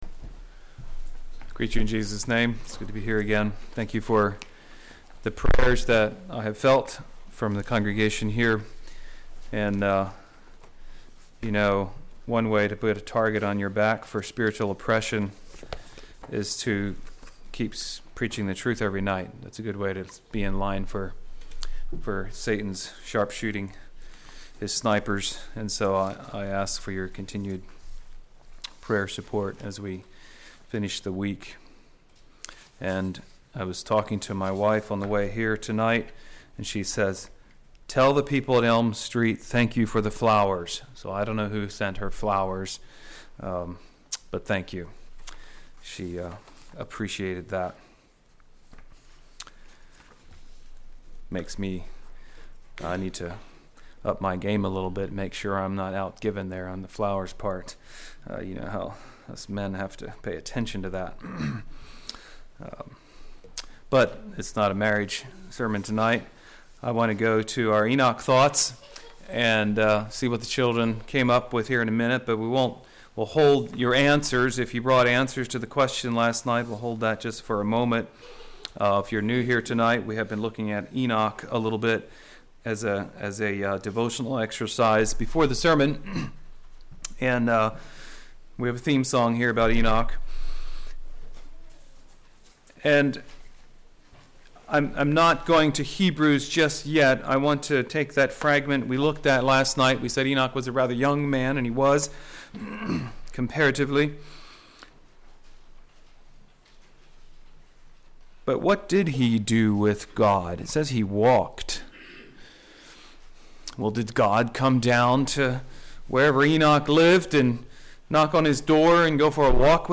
This sermon compares the Christian life to Jacob's ladder.